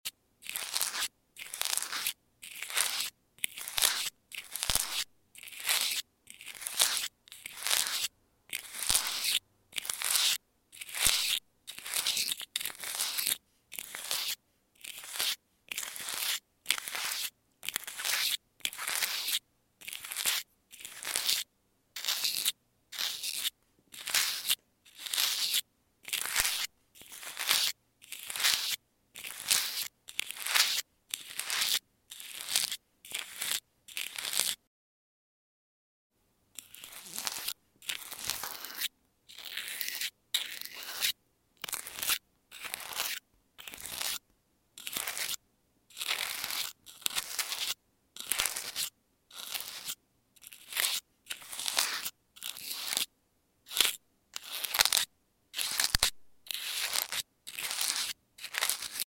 Face scratching with no talking